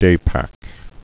(dāpăk)